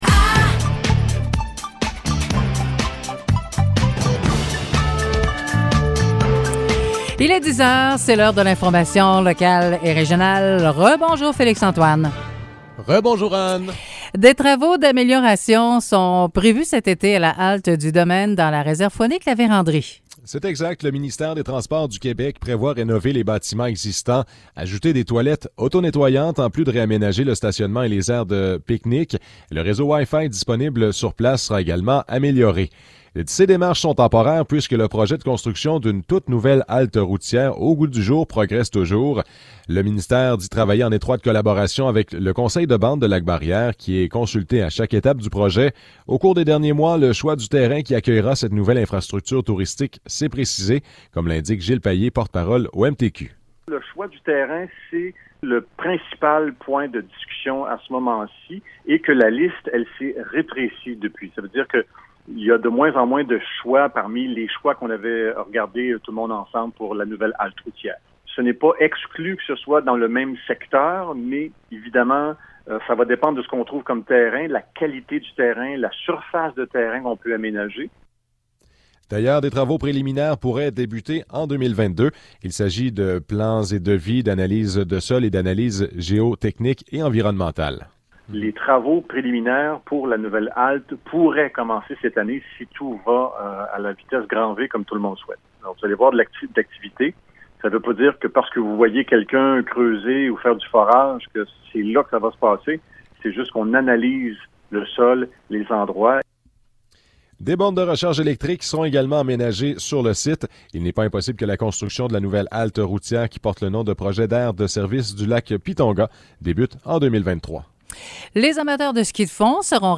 Nouvelles locales - 28 janvier 2022 - 10 h